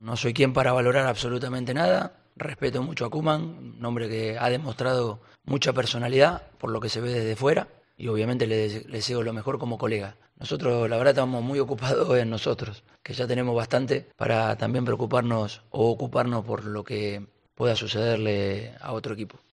EN RUEDA DE PRENSA
El técnico del Atlético de Madrid ha comparecido ante los medios en la previa del partido frente al Fútbol Club Barcelona.